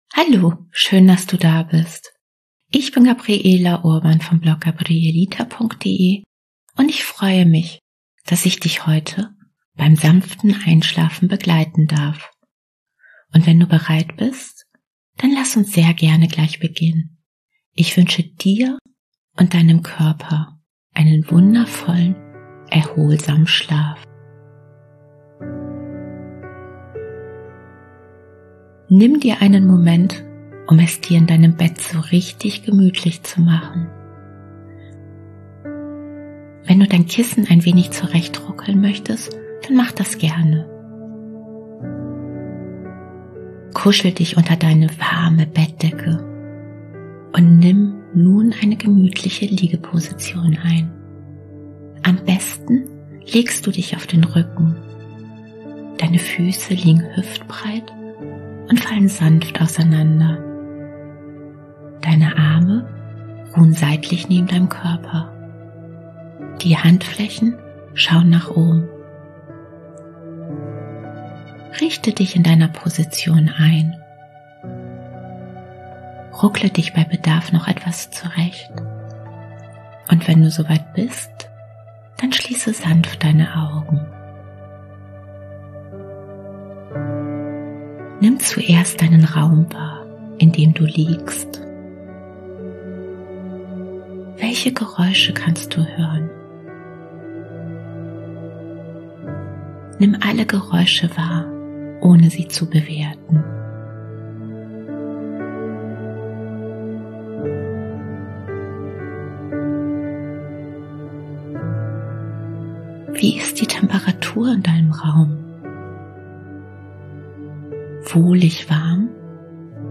In dieser 40 Minuten Einschlafmeditation mit einer Traumreise in die Berge liegt der Fokus auf dem Loslassen – sowohl körperlich loslassen als auch belastende Gedanken loslassen. Damit du zur Ruhe kommen kannst, sanft einschläfst und erholsam durchschläfst. Die geführte Meditation zum Einschlafen wird untermalt von wunderbar beruhigender Klaviermusik.